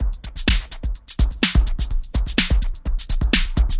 Shakers And Snare